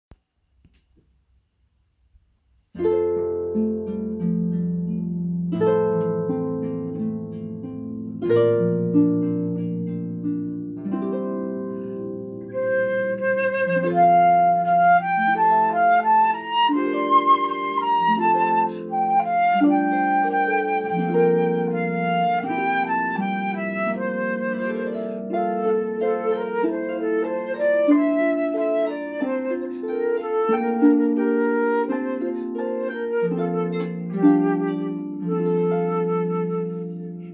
(Includes score, harp part, and flute/violin part.)